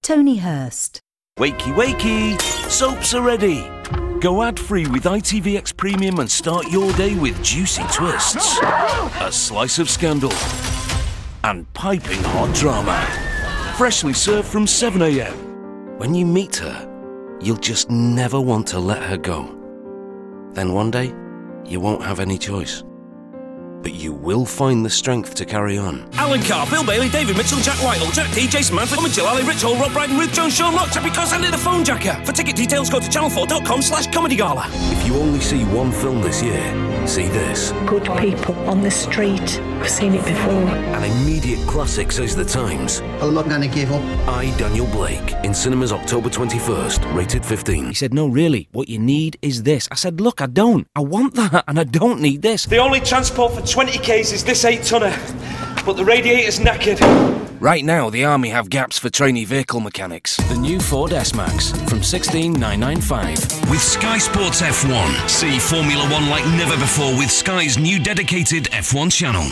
Description: Northern: captivating, confident, experienced
Commercial 0:00 / 0:00
American, Birmingham, Mancunian*, Northern*, Scottish, Scouse, West Midlands